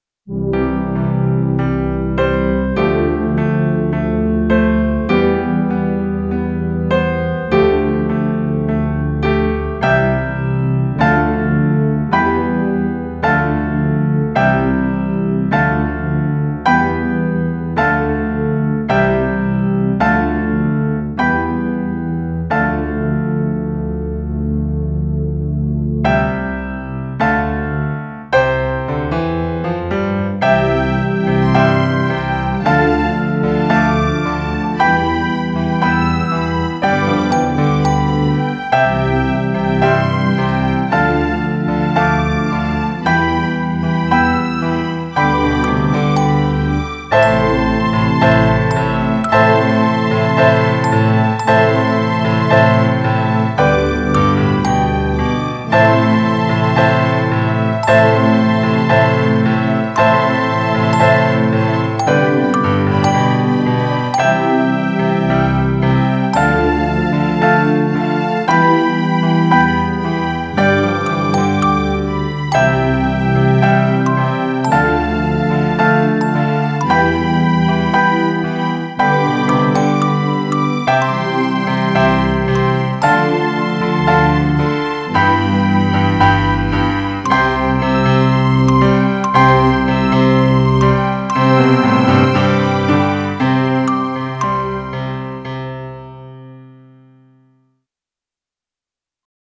Fashionista Girl Accompaniment